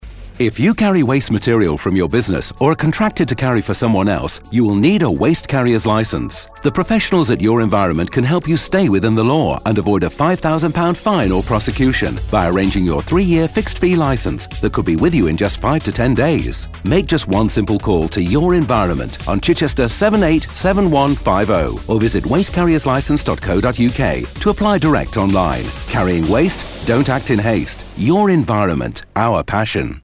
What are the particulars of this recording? As part of our continued expansion and investment programme in Waste Carriers Licensing, Your Environment has launched a new advert on our local radio station - Spirit FM!